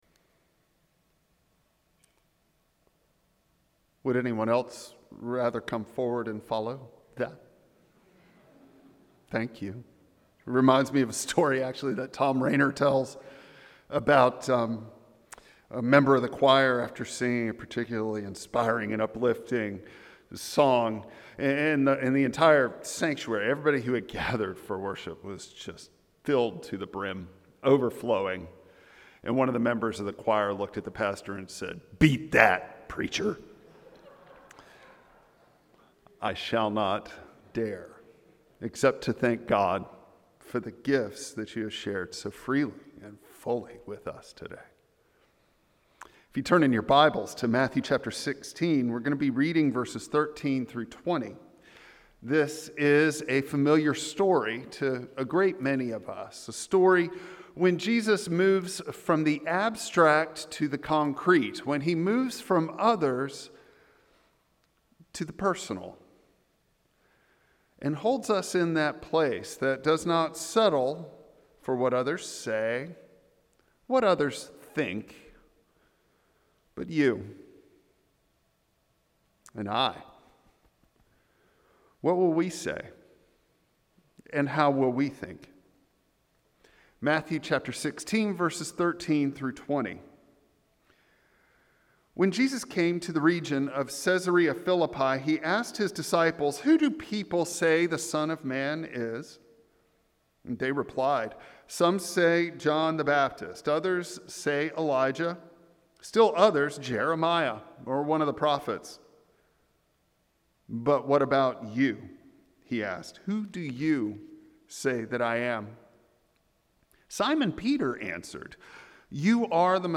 Matthew 16:13-20 Service Type: Traditional Service Bible Text